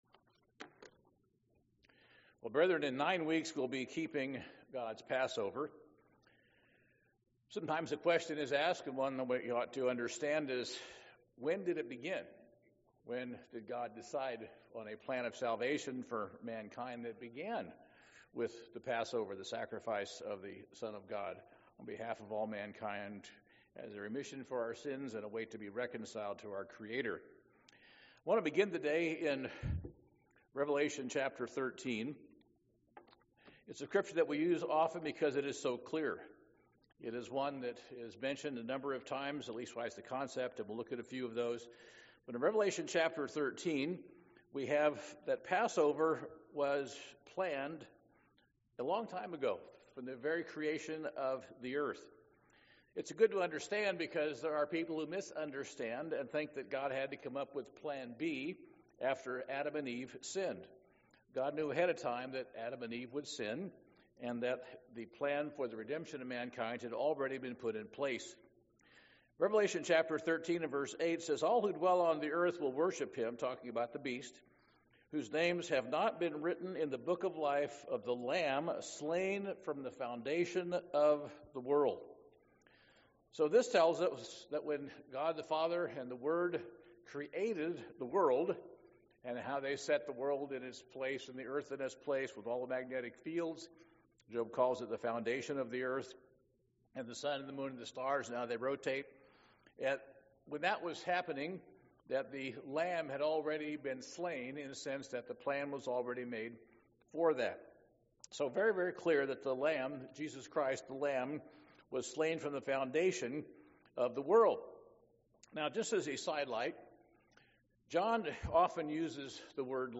Given in Portland, OR